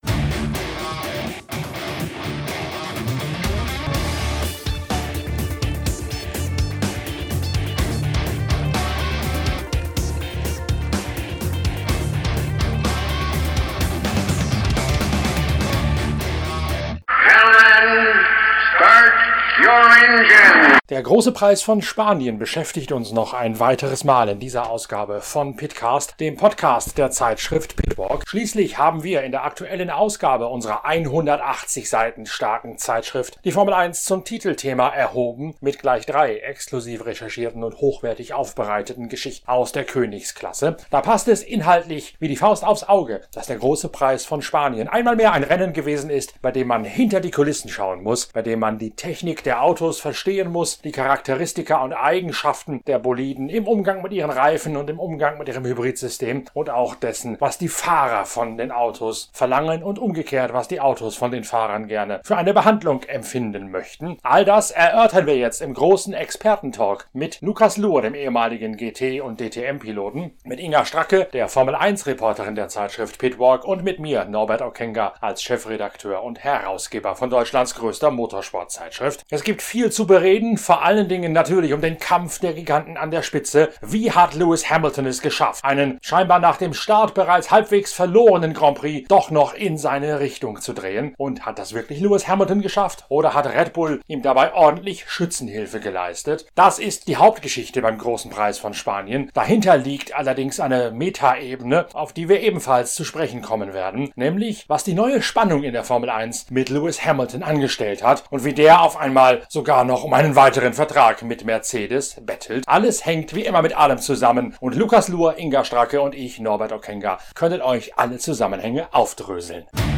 Das Expertengremium der Zeitschrift PITWALK erörtert in diesem Talk alle relevanten Fragen zum Kampf um den Sieg in Katalonien.